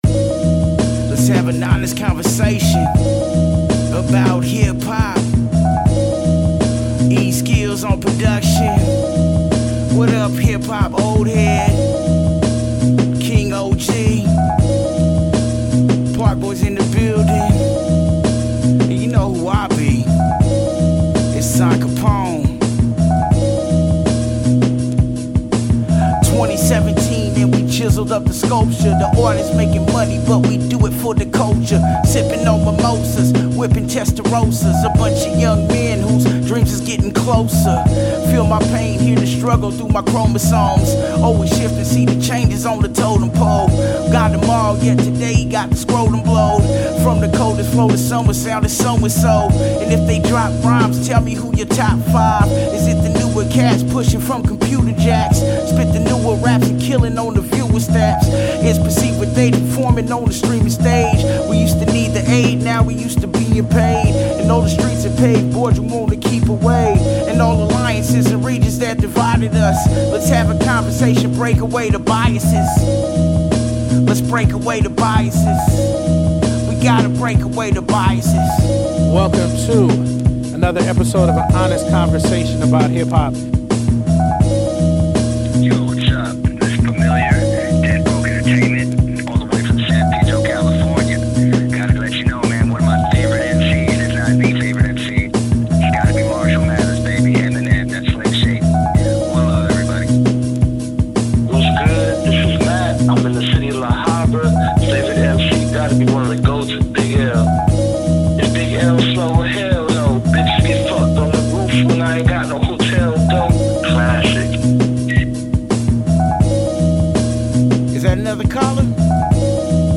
Outro